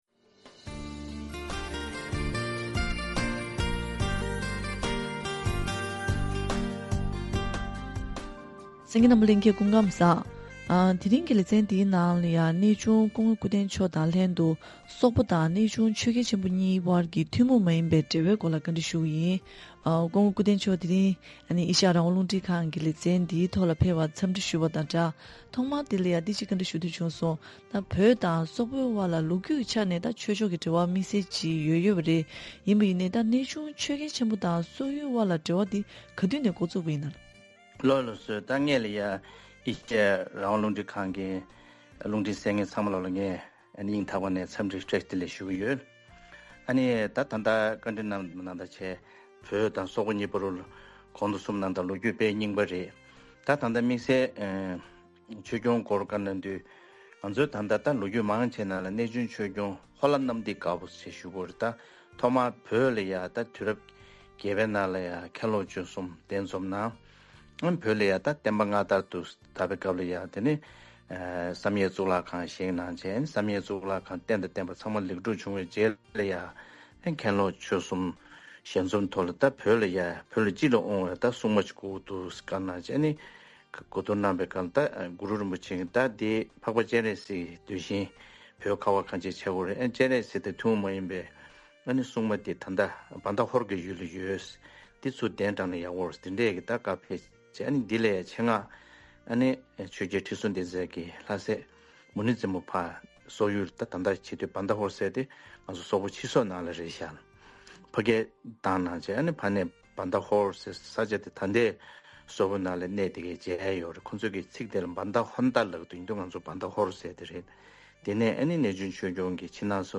བཀའ་དྲི་ཞུས་པའི་ལས་རིམ་དེ་གསན་གནང་གི་རེད།